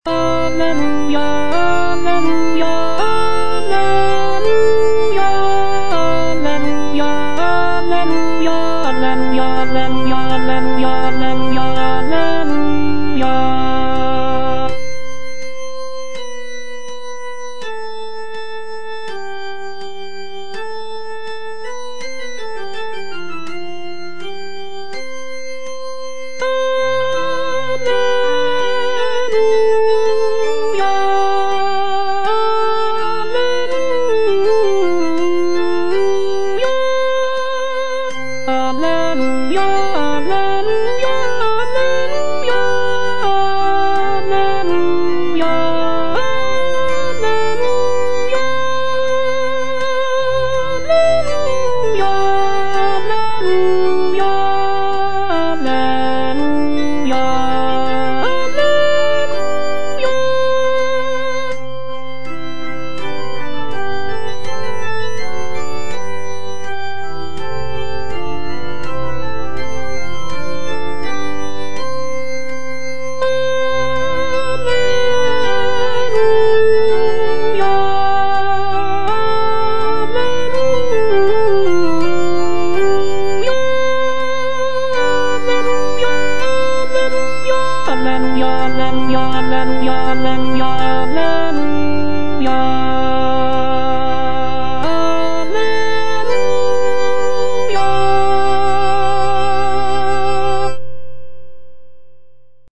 W. BOYCE - ALLELUIA Alto (Voice with metronome) Ads stop: auto-stop
"Alleluia" is a choral anthem composed by William Boyce, an English composer of the Baroque era. The piece is part of his larger body of sacred choral works and is written in a lively and joyful style. "Alleluia" features rich harmonies, soaring melodies, and intricate counterpoint, typical of Boyce's compositional style.